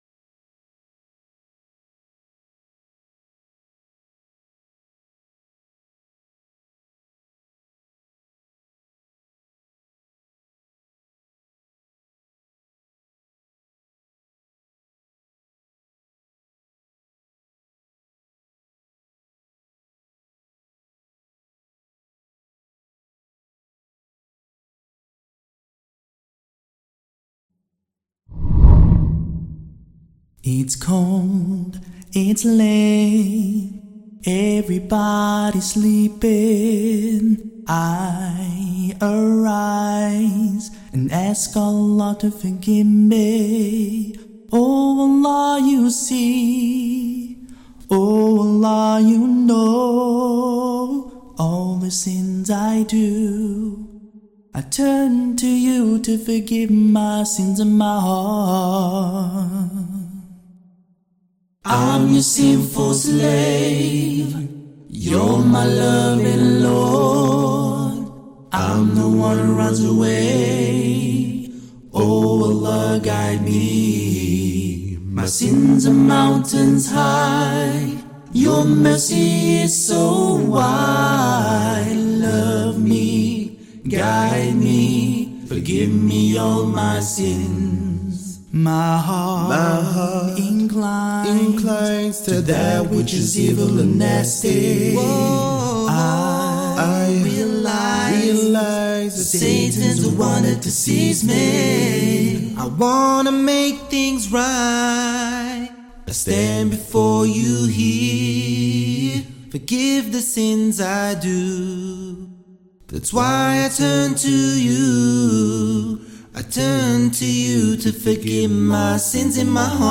Sinful Slave – Nasheed